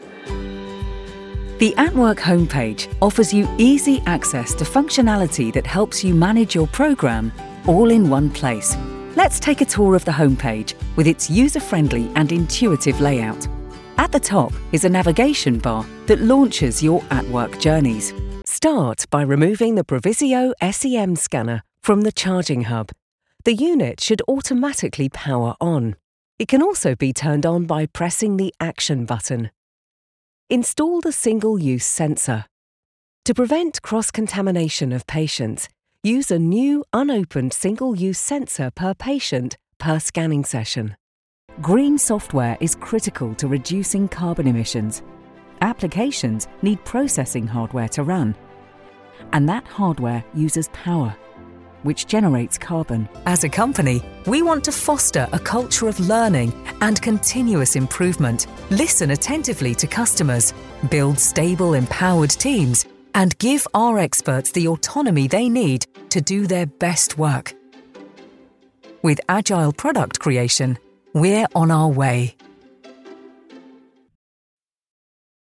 English (British)
Deep, Natural, Mature, Friendly, Warm
Explainer